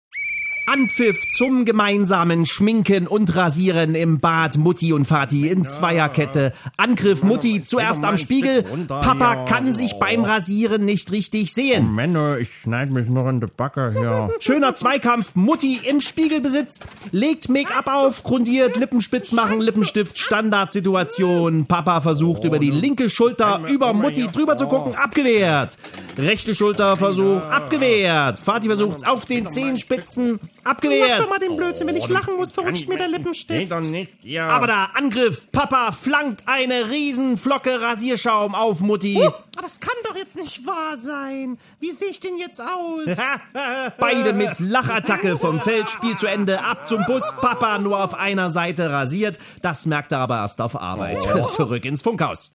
Er kann nicht anders: Er muss alles was morgens in Deiner Familie passiert kommentrieren: Als Fußballspiel!